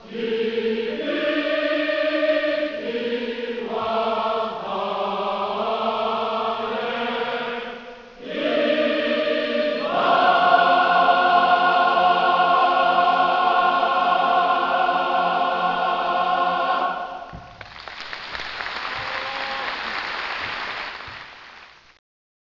2001/9/8 : 京大合唱団創立70周年演奏会出演
朝日新聞京都版でも報道されたこの演奏会、観客数も800名を越えて京都コンサートホールの１階席はほぼ満員と盛況であった。